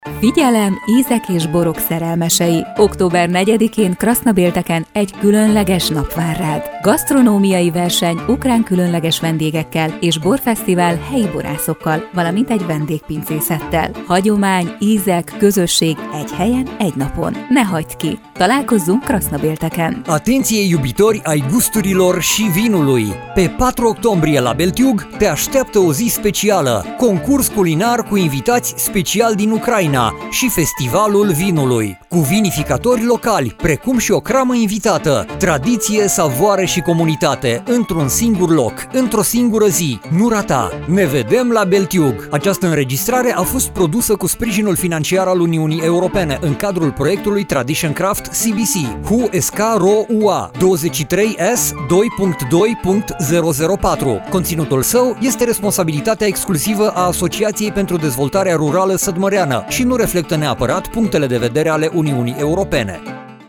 Radio spot - Concurs culinar si festival de vin